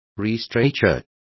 Complete with pronunciation of the translation of restaurateur.